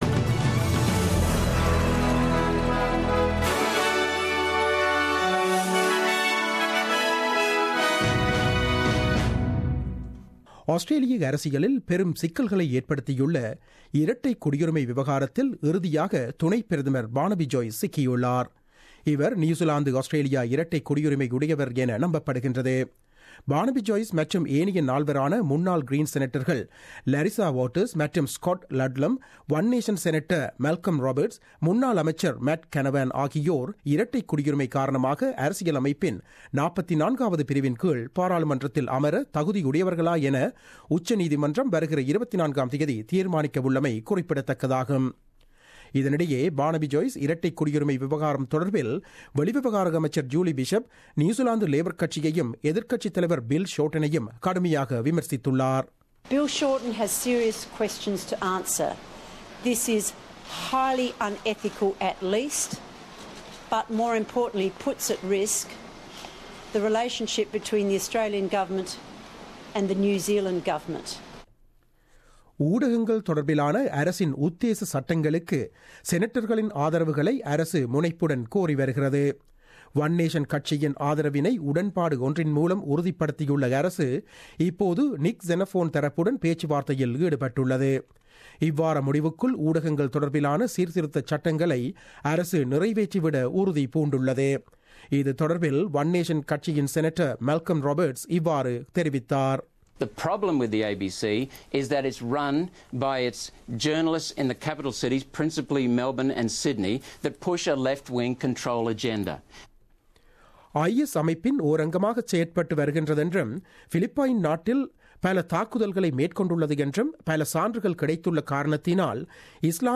The news bulletin broadcasted on 16 August 2017 at 8pm.